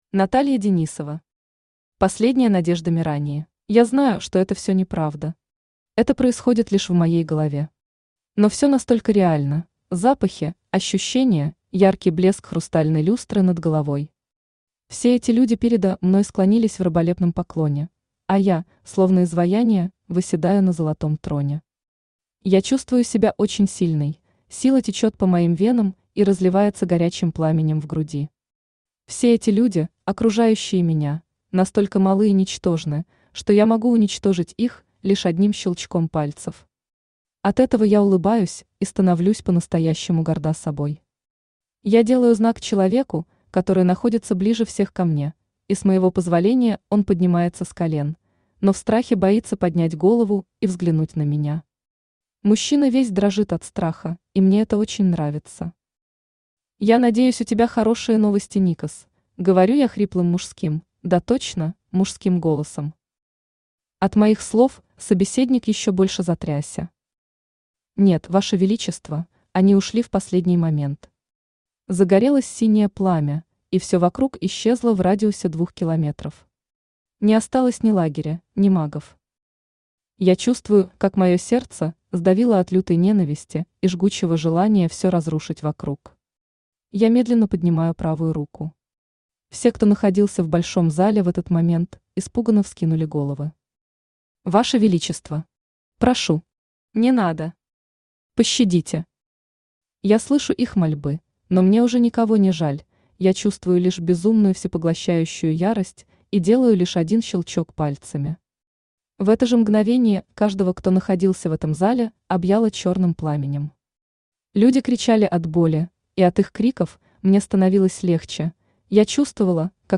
Аудиокнига Последняя надежда Мирании | Библиотека аудиокниг
Aудиокнига Последняя надежда Мирании Автор Наталья Денисова Читает аудиокнигу Авточтец ЛитРес.